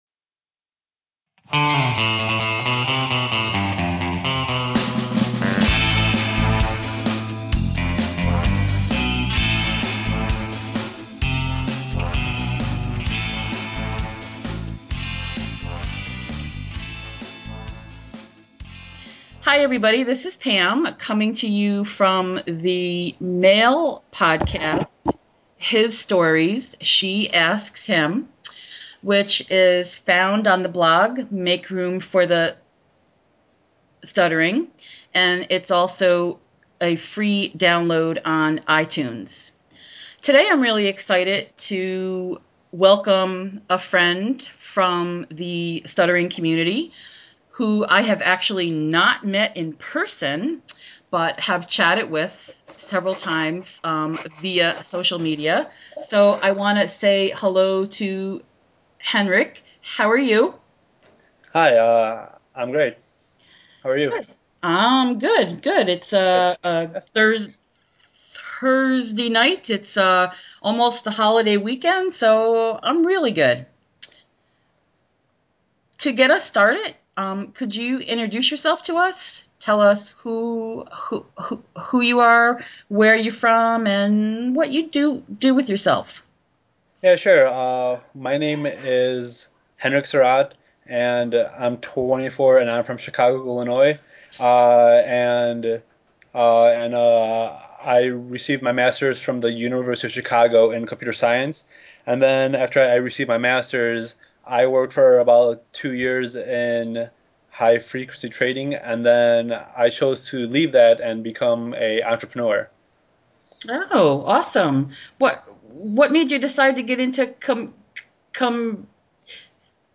We have a great time getting to know each other in this conversation and focus on humor and not taking self too seriously.